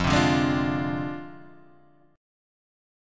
F Minor 6th